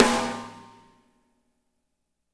-MEDSNR2N -L.wav